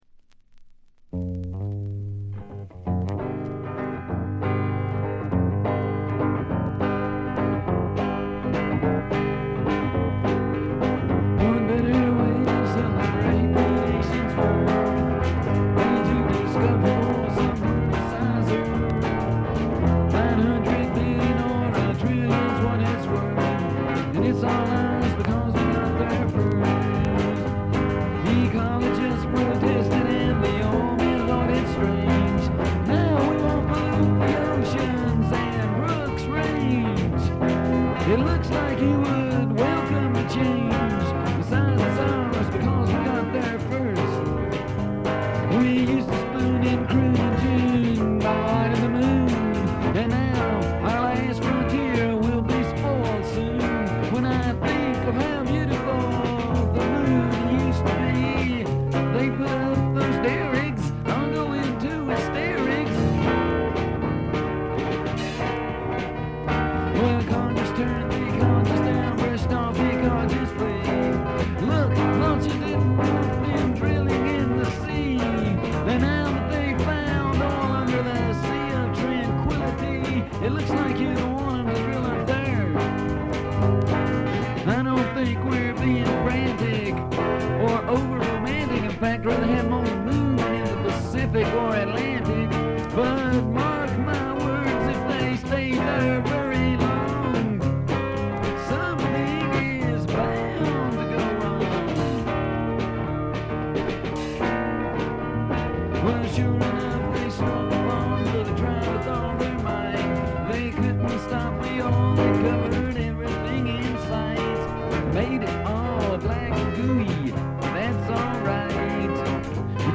A1頭で4連のプツ音。これ以外は軽微なチリプチが少しありますがプレス起因のものでしょう。
ヴァージニア州の3人組が密かに残したウルトラ・グレイトな自主フォーク名盤で、今世紀に入ってから騒がれ出したレコードです。
音質的にはラジカセより少しはマシってレベルです。
試聴曲は現品からの取り込み音源です。